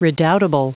Prononciation du mot redoubtable en anglais (fichier audio)
Prononciation du mot : redoubtable